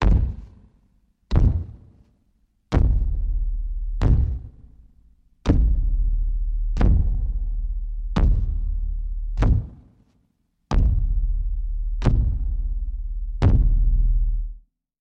Звуки великана
Здесь собраны впечатляющие аудиоэффекты: гулкие шаги, низкие рыки, скрип древних деревьев под тяжестью гигантов.
Звук топота великана